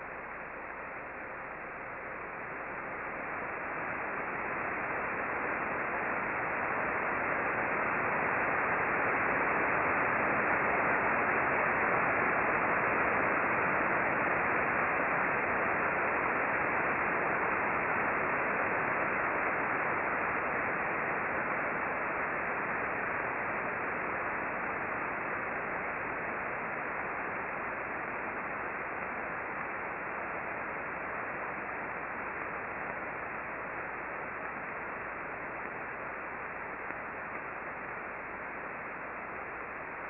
Click here for a recording of receiver audio between 1633:08 and 1633:48, corresponding to the trace above